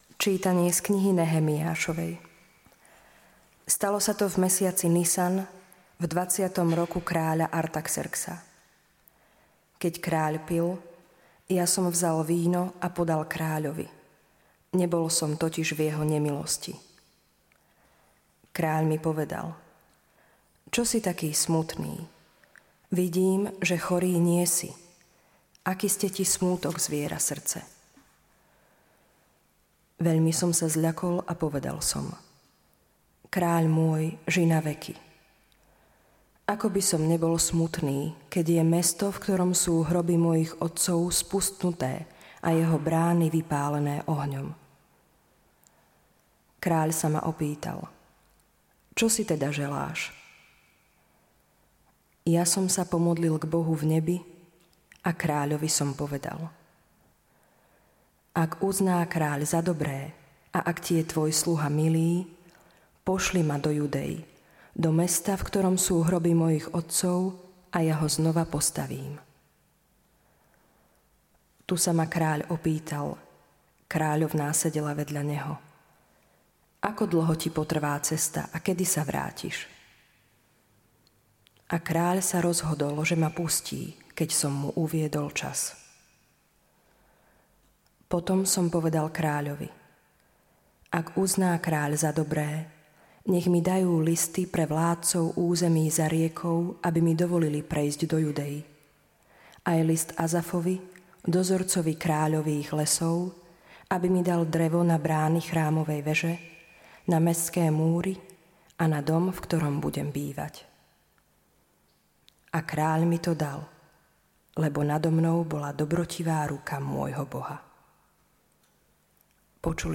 LITURGICKÉ ČÍTANIA | 1. októbra 2025